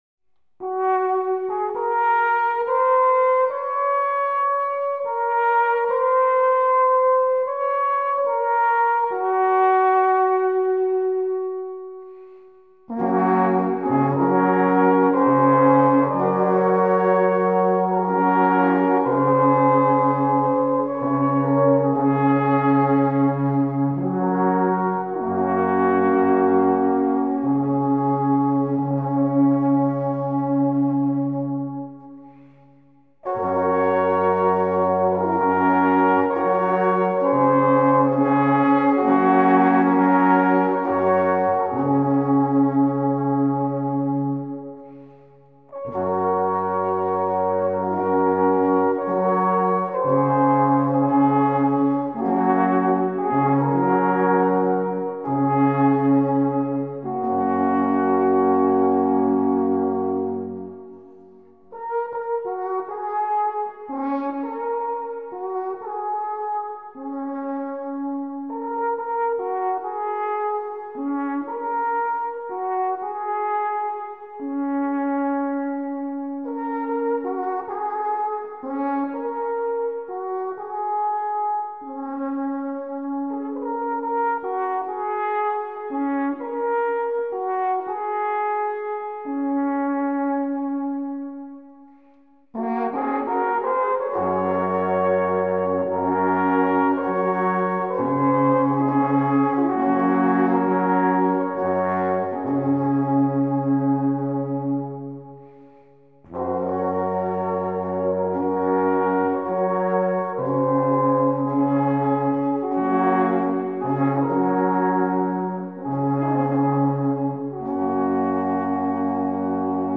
A Swiss National Yodeling Festival
Alphornquartett Düdingen: D'r Fryburger Chuereihe